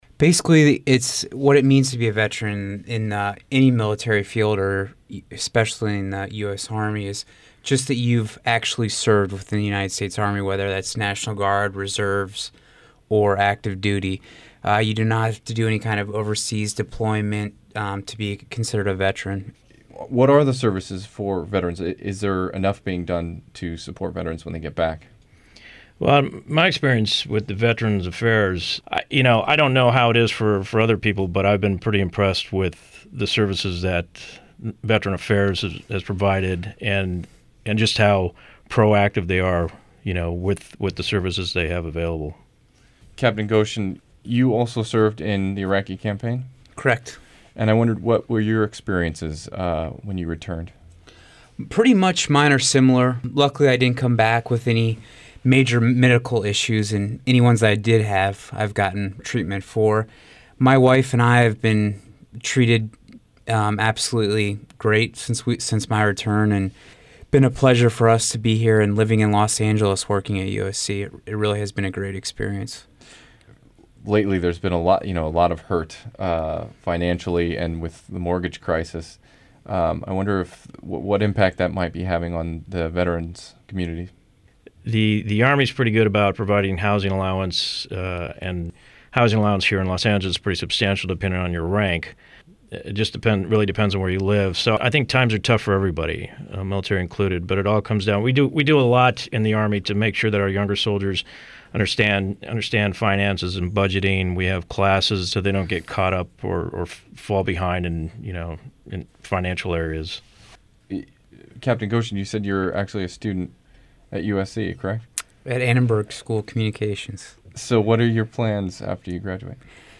iraqvetsinterview.mp3